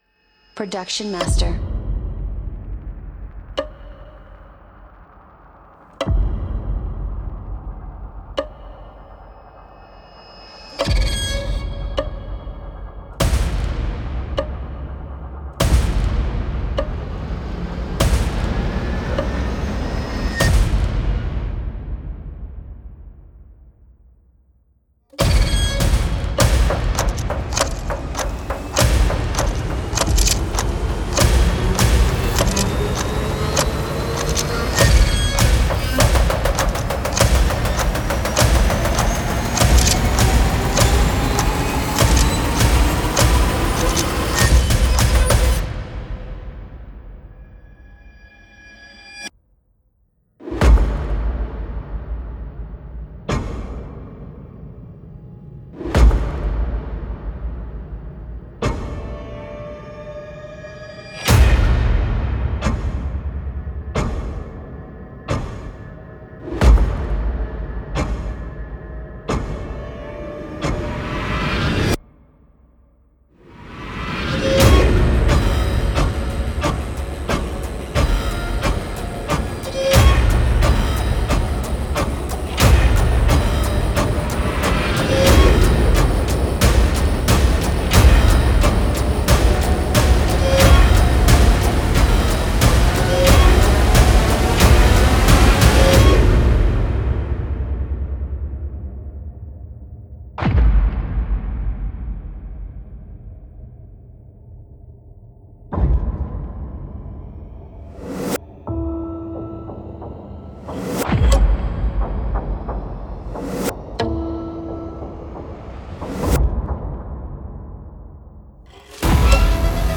古老的闹鬼时钟和诡异的电影效果FX声音和循环将近在咫尺，为任何项目增添了恐怖感。
黑暗的电影鼓：  充满黑暗的打击乐，恐怖的轰鸣声和幽灵的打击乐循环，这个黑暗的电影样本集将使所有观众惊呆。
此包包含高品质的单声鼓声和循环声，错综复杂的乐器单声声和循环声，创意的质感，缠绵的挥舞声，令人恐惧的fx以及您下一次电影般的恐怖逃脱所需要的一切。